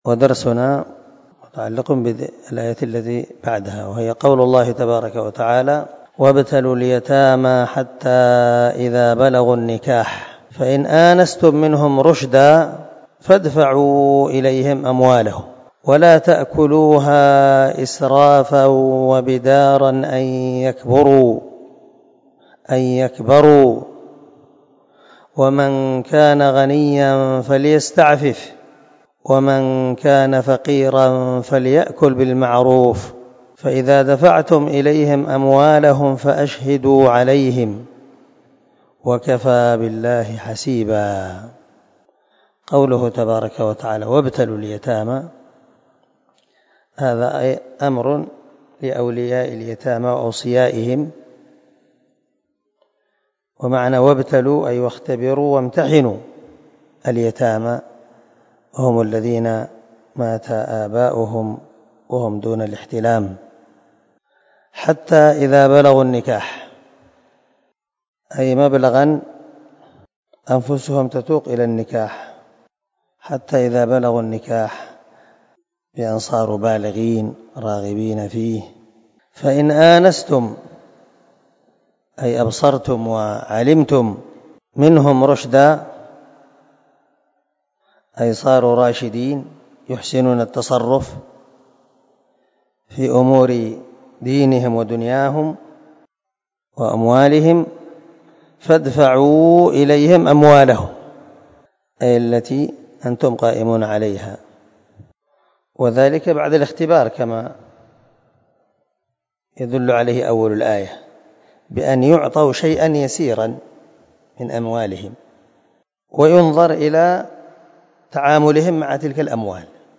237الدرس 5 تفسير آية ( 6 ) من سورة النساء من تفسير القران الكريم مع قراءة لتفسير السعدي